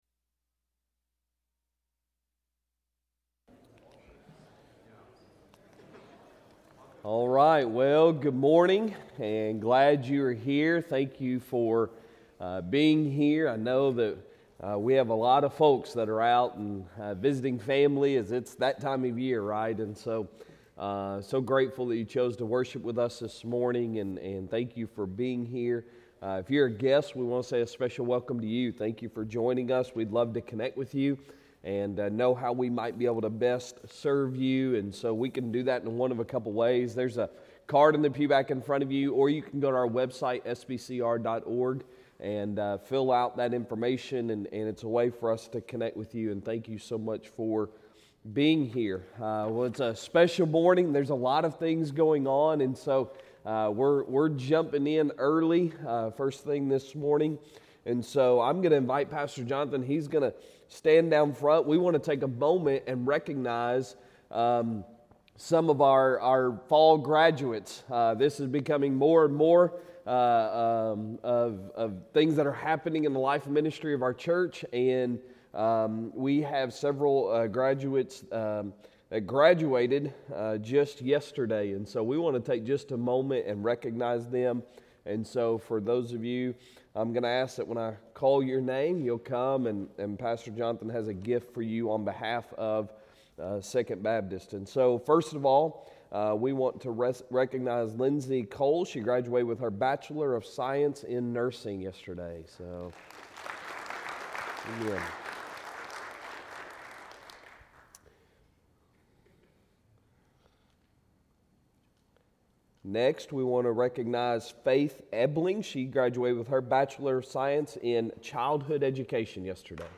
Sunday Sermon December 17, 2023